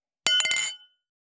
326ガラスのグラス,ウイスキー,コップ,食器,テーブル,チーン,カラン,キン,コーン,チリリン,